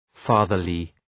Shkrimi fonetik {‘fɑ:ðərlı}